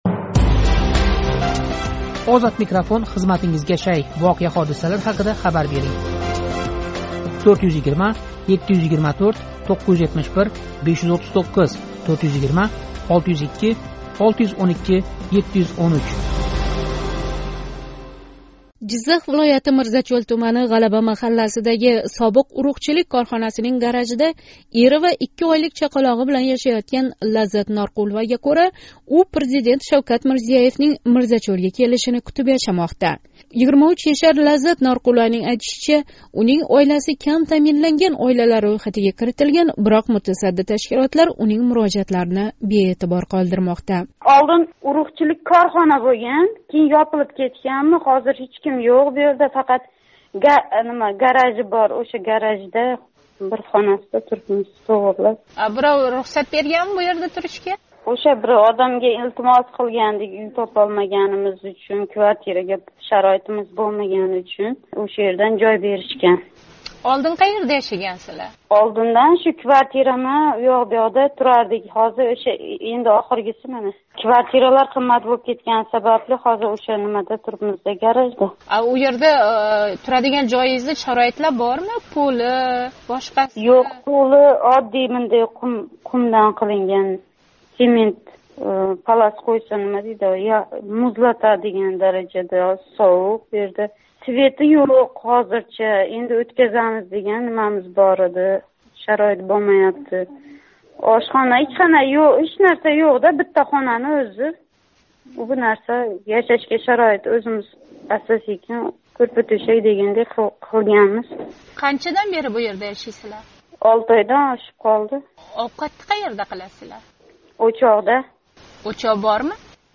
билан Озодлик радиоси